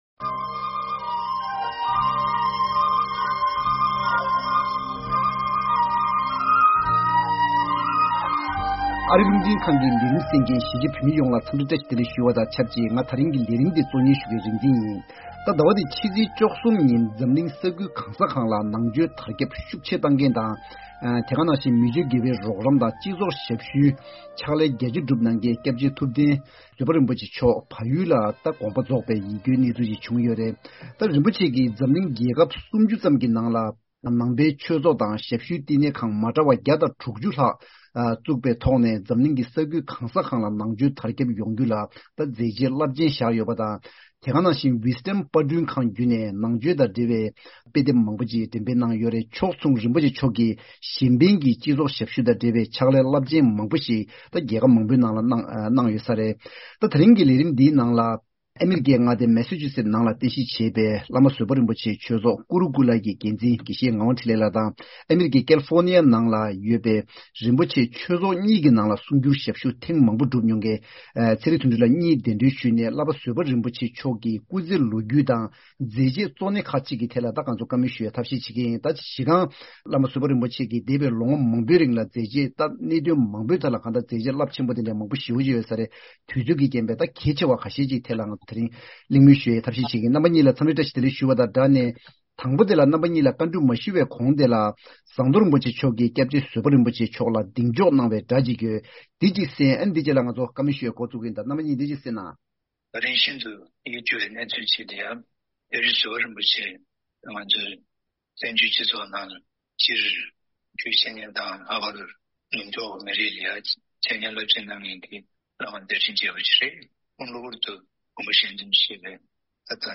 ༄༅། །ཉེ་དུས་བལ་ཡུལ་དུ་སྐྱབས་རྗེ་ཐུབ་བསྟན་བཟོད་པ་རིན་པོ་ཆེ་མཆོག་དགོངས་པ་ཆོས་དབྱིས་སུ་འཐིམས་ཏེ་ད་ཆ་ཐུགས་དམ་ལ་བཞུགས་ཡོད་པ་དང་ ཁོང་གི་སྐུ་ཚེའི་ལོ་རྒྱུས་དང་། རིན་པོ་ཆེ་མཆོག་གི་མཛད་རྗེས་ཁག་གཅིག་ཐད་ལ་འབྲེལ་ཡོད་གཉིས་གདན་ཞུ་བྱས་ཏེ་གླེང་མོལ་ཞུ་ཡི་ཡིན།